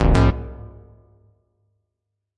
标签： 选择 单击 密码 登录 游戏 上网 正确 登录 菜单 选项
声道立体声